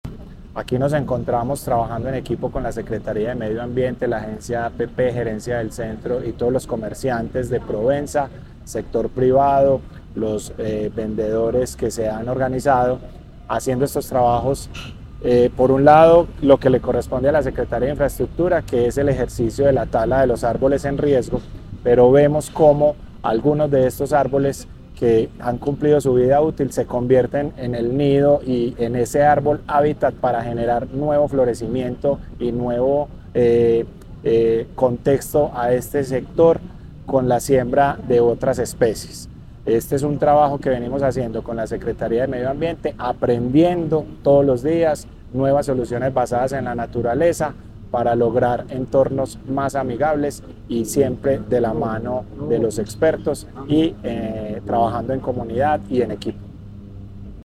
Declaraciones secretario de Infraestructura Física, Jaime Naranjo
Declaraciones-secretario-de-Infraestructura-Fisica-Jaime-Naranjo.mp3